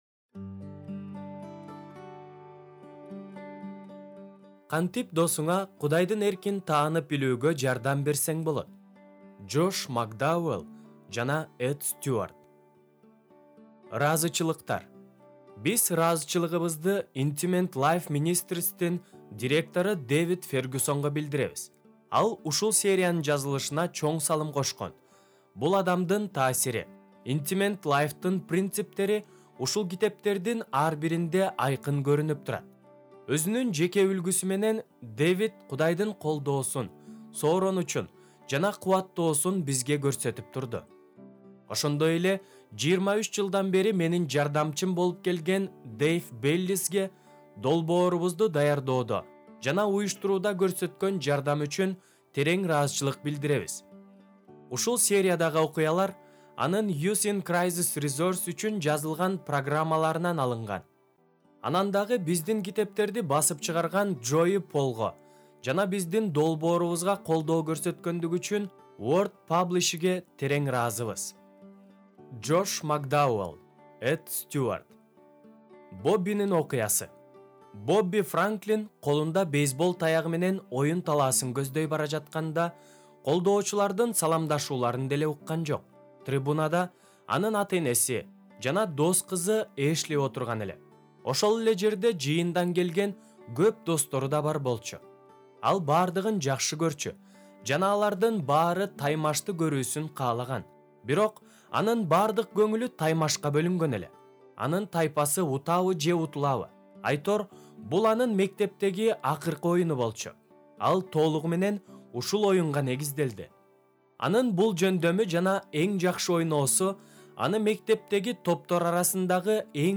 Аудио-китеп угуу